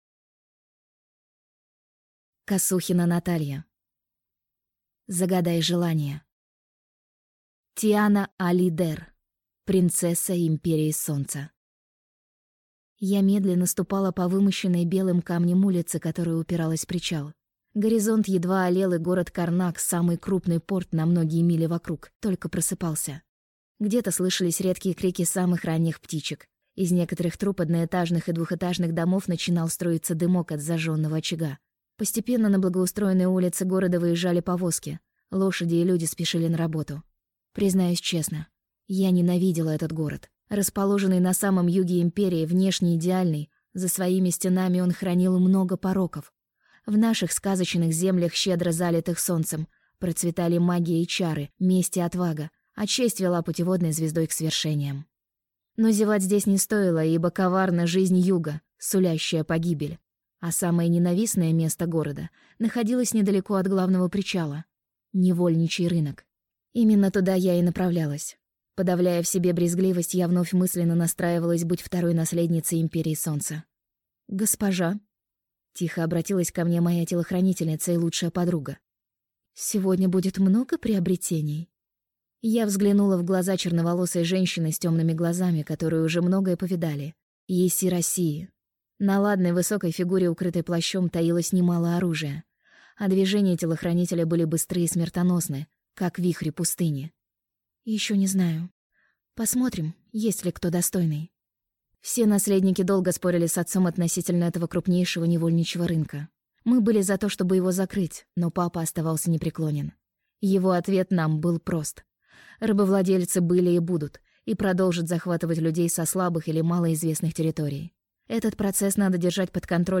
Аудиокнига Загадай желание | Библиотека аудиокниг